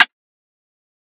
[MAMA] - RIM SNARE.wav